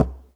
Knock11.wav